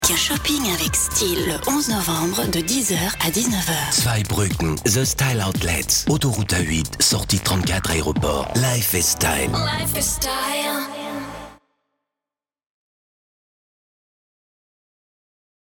Schauspieler, Sprecher, Regisseur etc .....
Sprechprobe: Werbung (Muttersprache):